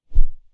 Close Combat Swing Sound 32.wav